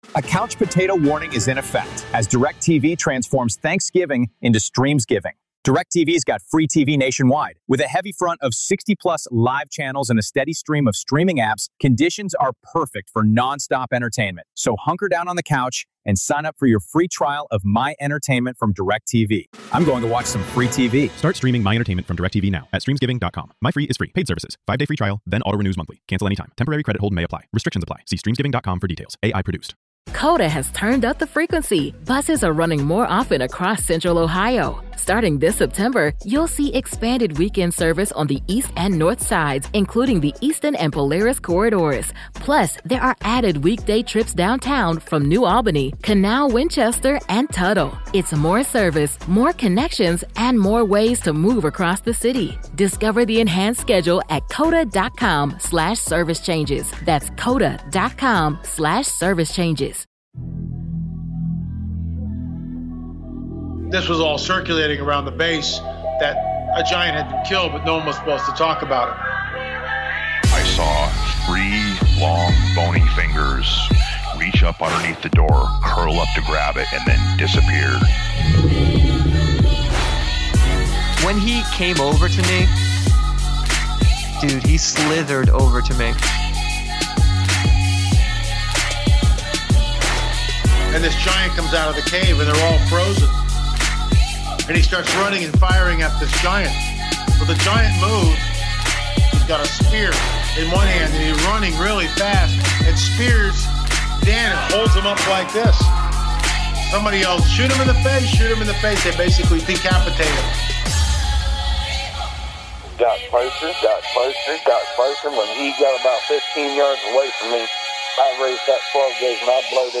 Join us as we talk with him about his wild seance experience, including a powerful force that entered the whole property, caused faces and voices to change, and filled everyone present with extreme fear and shock!